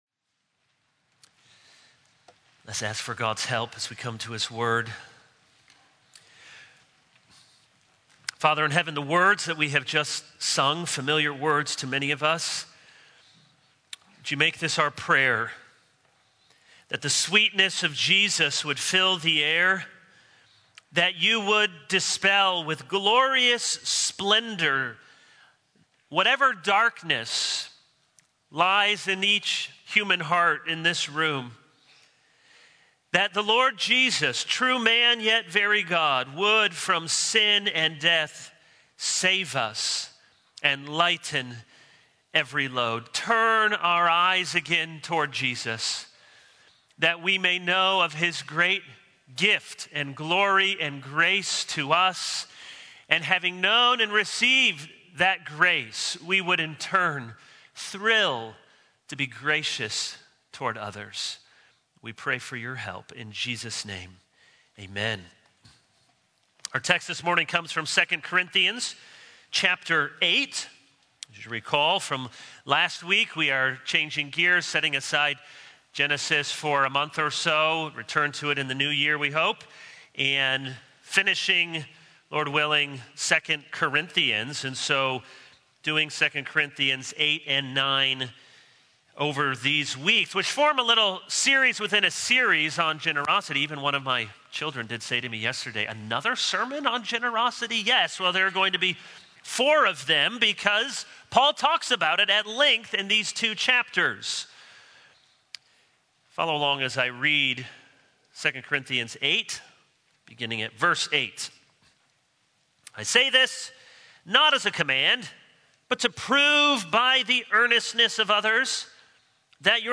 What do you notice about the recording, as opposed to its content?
November 28, 2021 | Sunday Morning